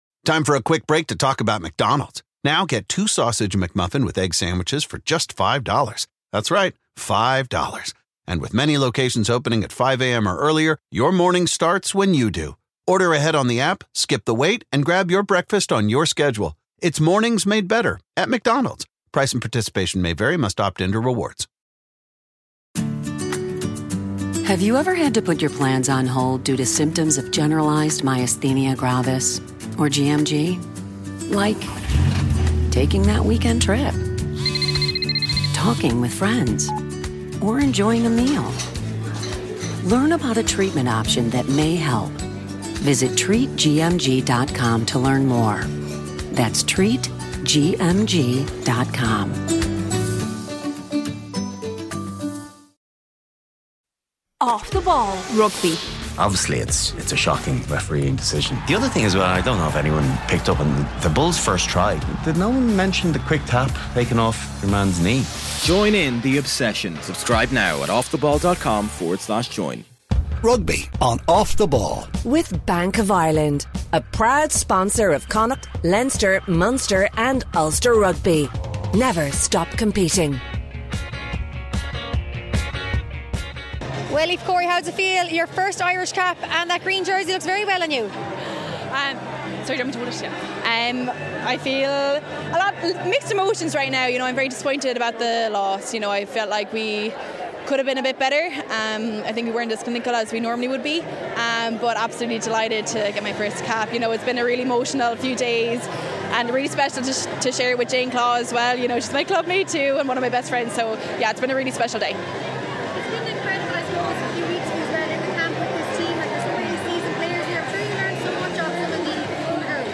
W6N Post-Match Interviews